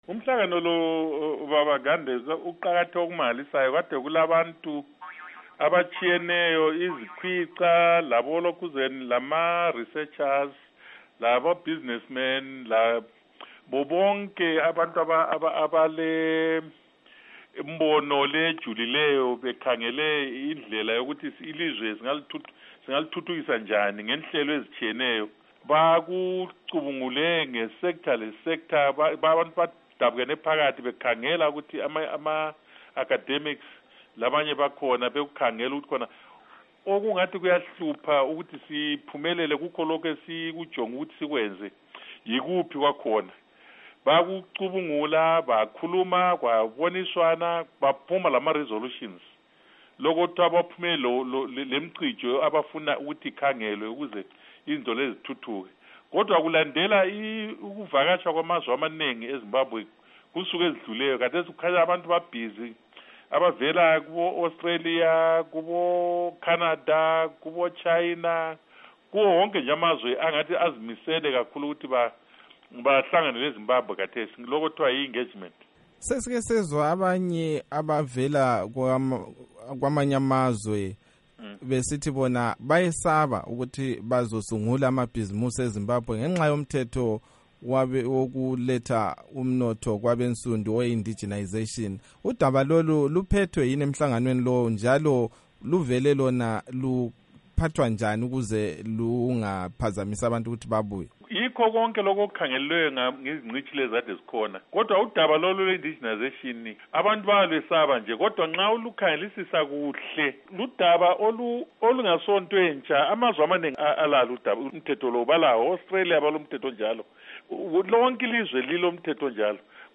Ingxoxo loMnu. Obert Mpofu